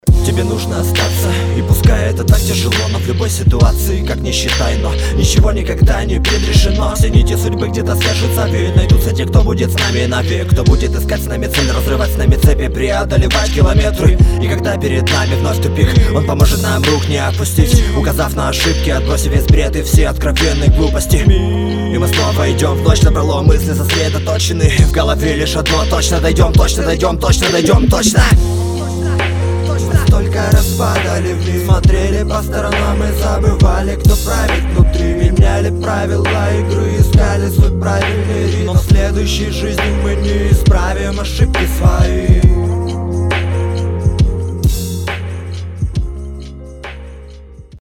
Приятно, цельно, красиво, атмосферно.